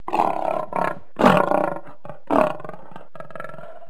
Lioness growl